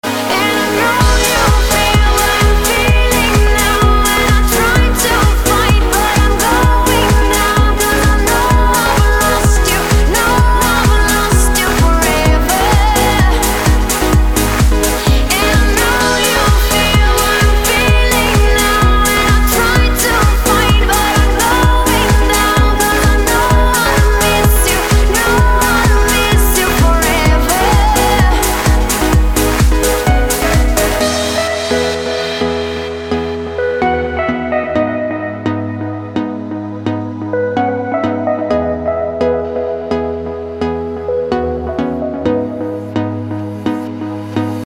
красивые
женский вокал
dance
vocal